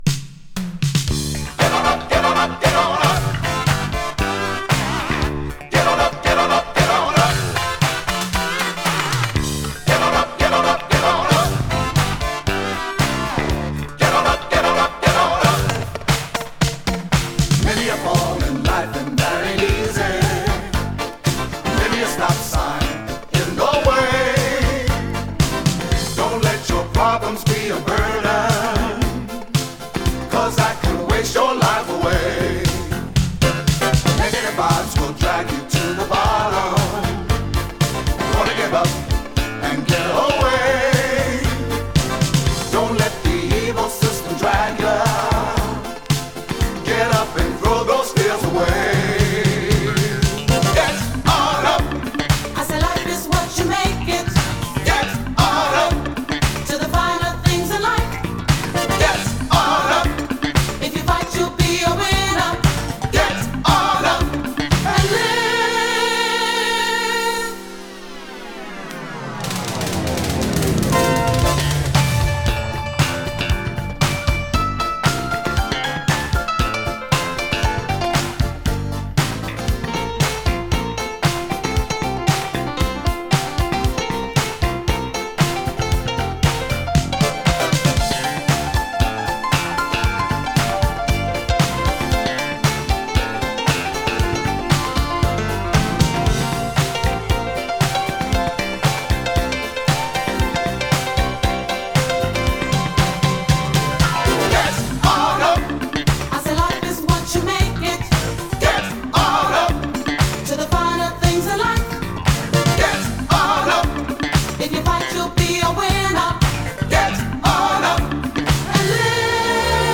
German Funky Disco!
ラップと女性コーラスが印象的でファンキーなディスコ・トラック！ピアノがエモい。